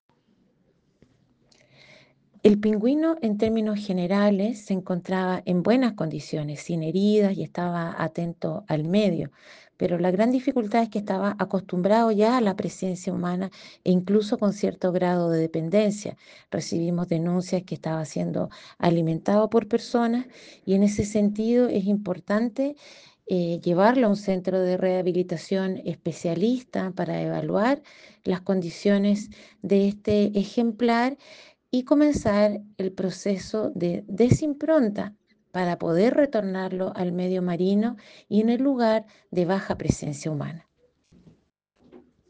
Cecilia Solís, Directora Regional de Sernapesca Coquimbo, se refirió a las condiciones en las que fue hallado el espécimen.
CU-Cecilia-Solis-x-Rescate-Pinguino-en-Los-Vilos.mp3